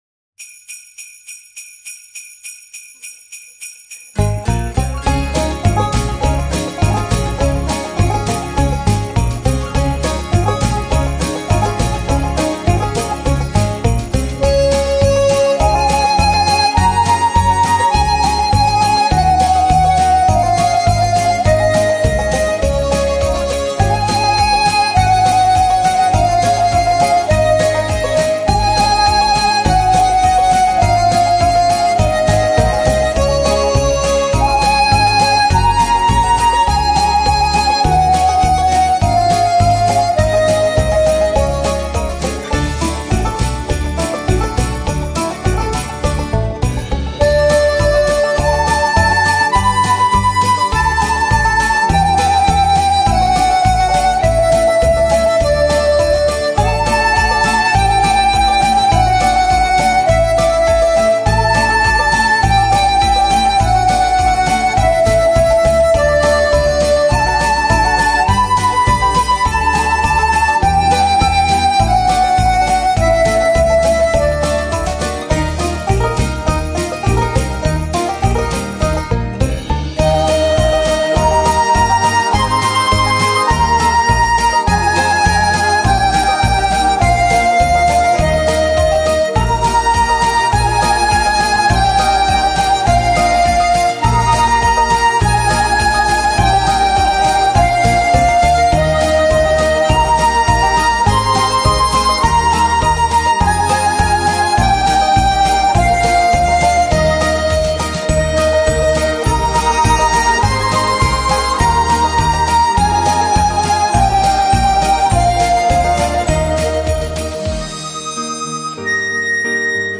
Жанр: Instrumental [Губная гармошка]